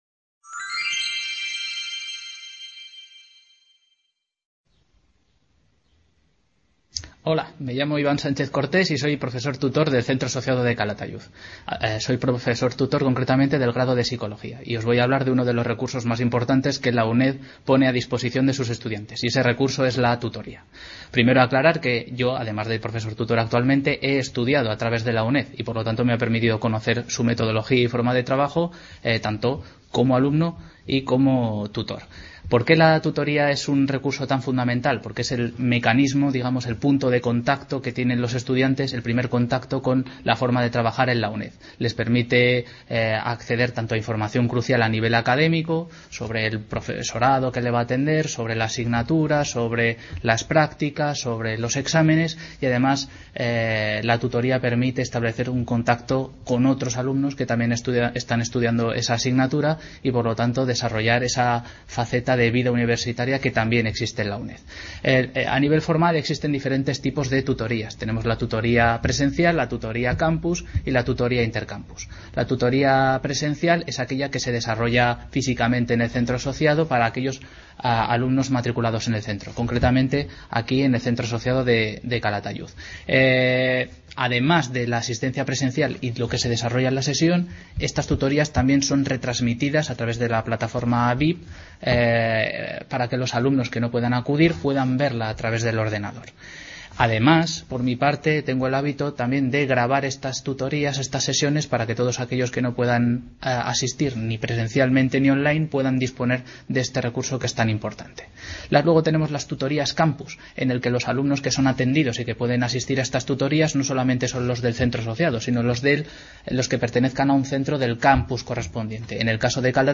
Pero, ¿qué supone la tutoría para un profesor tutor en una universidad como la UNED? ¿cómo perciben al estudiante de la UNED? En esta presentación, vamos a conocer algunas reflexiones en torno a este tema de un profesor tutor en el Grado de Psicología y en C.A. de Calatayud.
Video Clase